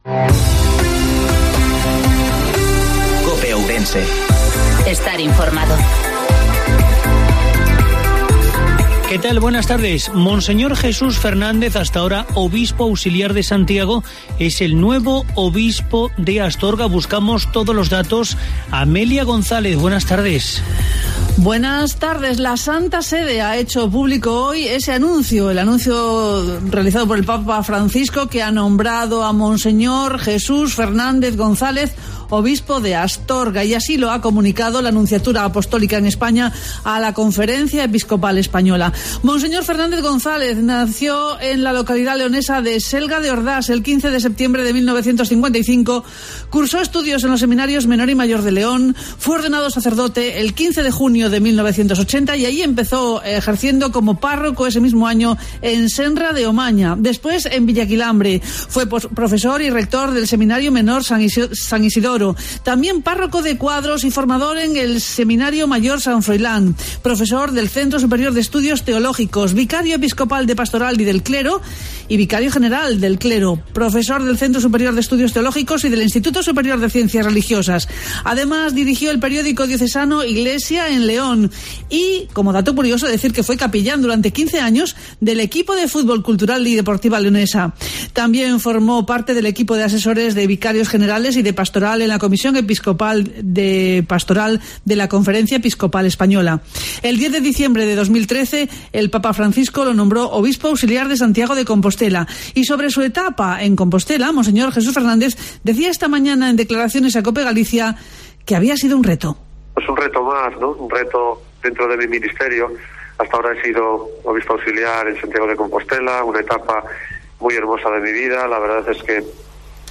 INFORMATIVO MEDIODIOA COPE OURENSE